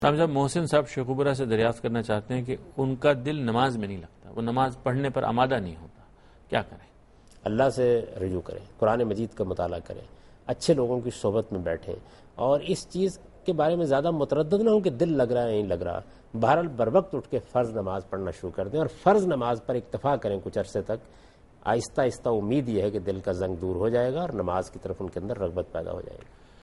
Category: TV Programs / Dunya News / Deen-o-Daanish /